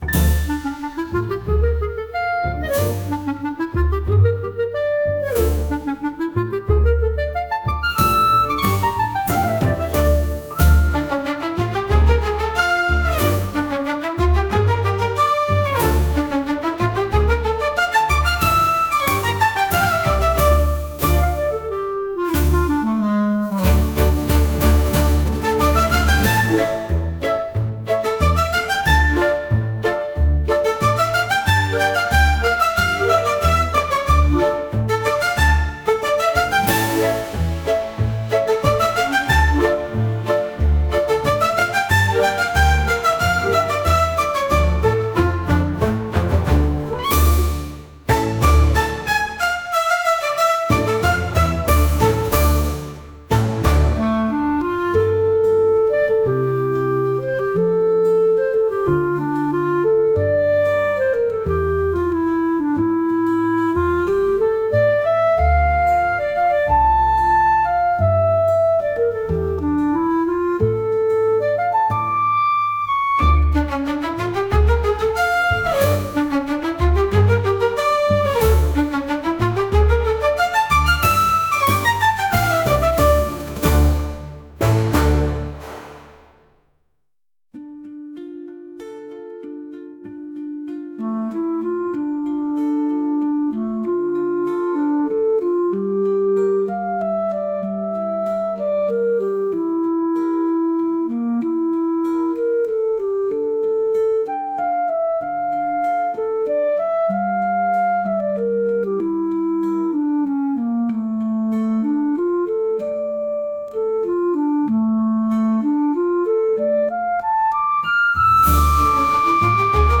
ちょっととぼけたようなオーケストラ曲です。